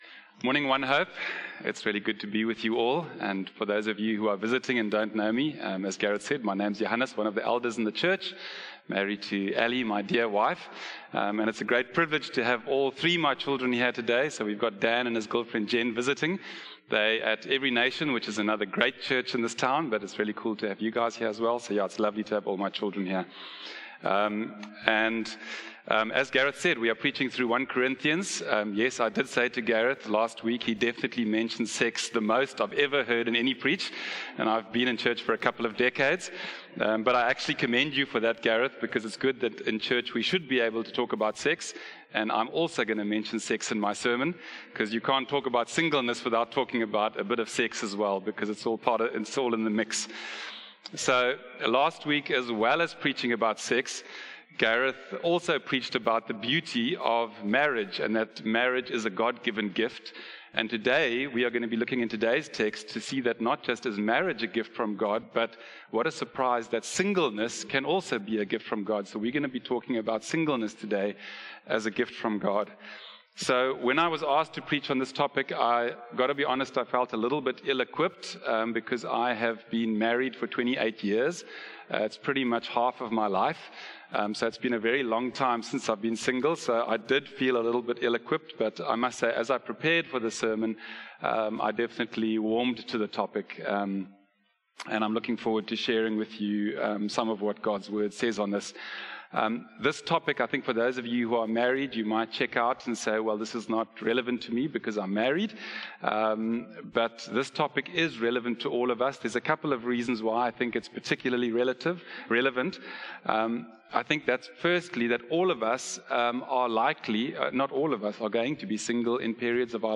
One-Hope-Sermon-3-August-2025.mp3